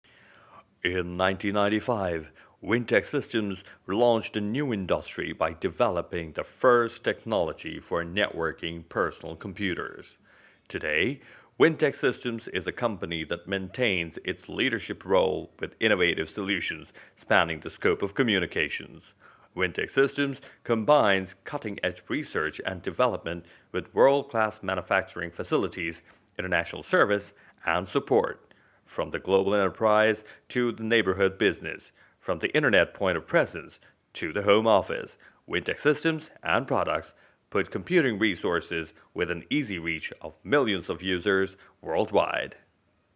Barriton Male Voice Over Artist
britisch